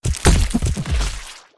Death1.wav